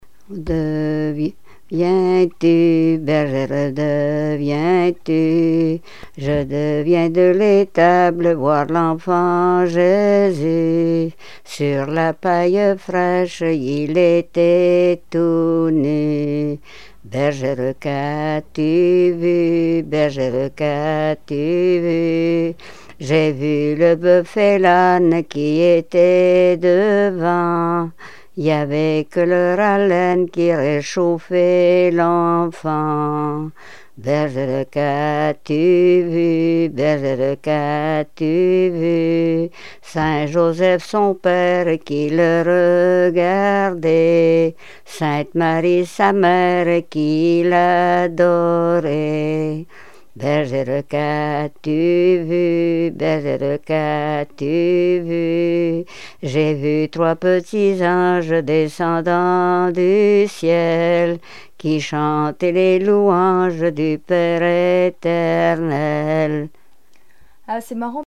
Mémoires et Patrimoines vivants - RaddO est une base de données d'archives iconographiques et sonores.
Genre dialogue
Répertoire de chansons traditionnelles et populaires
Pièce musicale inédite